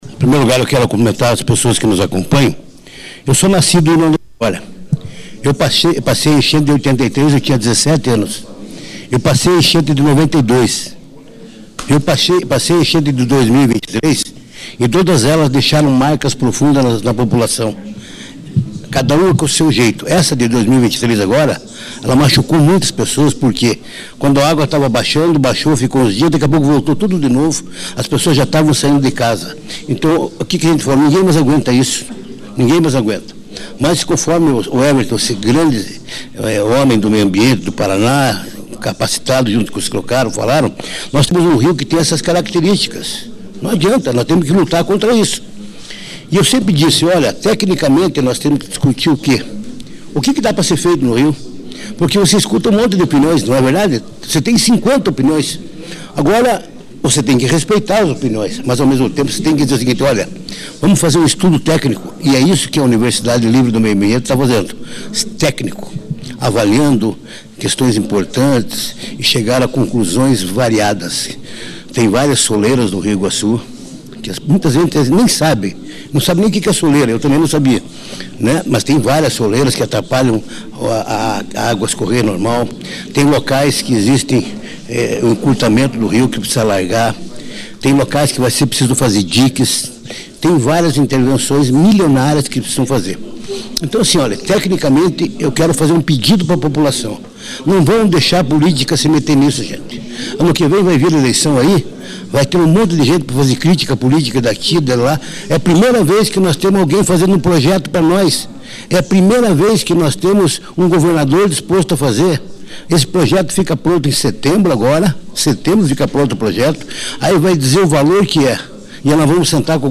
Durante a coletiva, foi destacado que a geografia de União da Vitória — localizada em uma área de confluência e cercada por morros — a torna naturalmente mais suscetível a alagamentos severos.
08-deputado-hussein.mp3